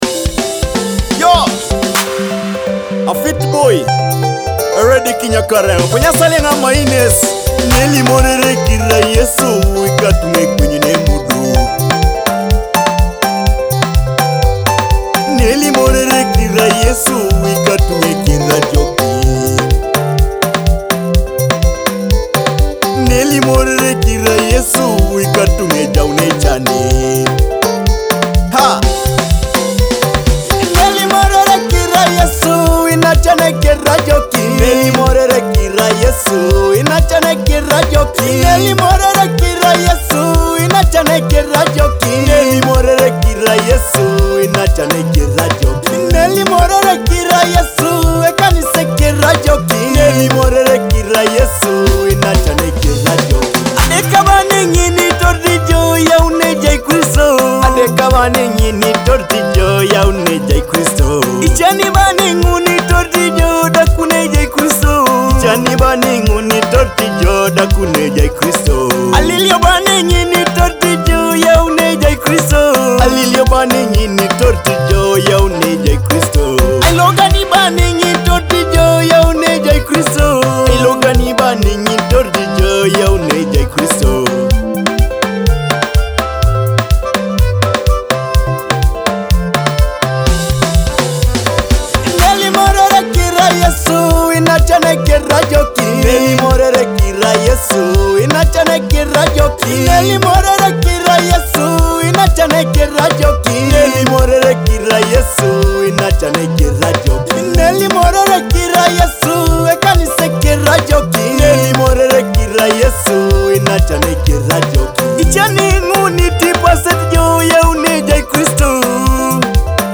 a powerful Teso gospel praise song.
With heartfelt vocals and inspiring lyrics